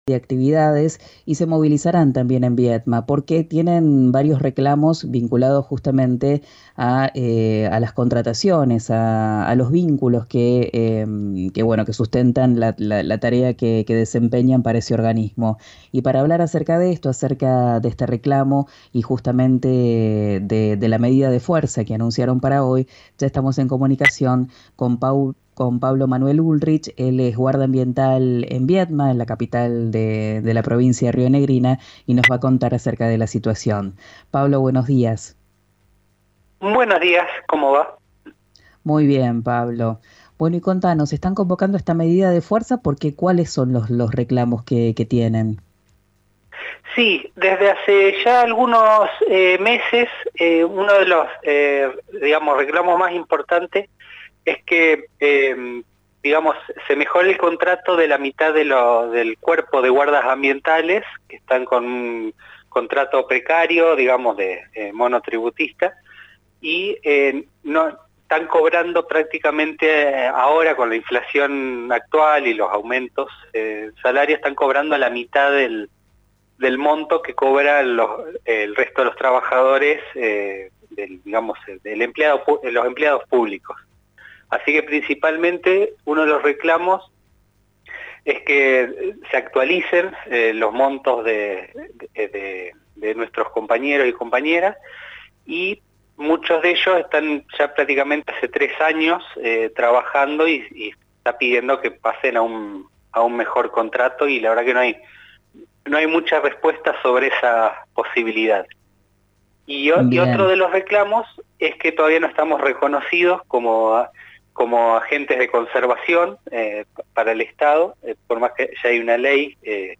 uno de los guarda ambientales de la capital provincial dialogó con RÍO NEGRO RADIO y señaló que están reclamando «por un mejor contrato para los guardas ambientales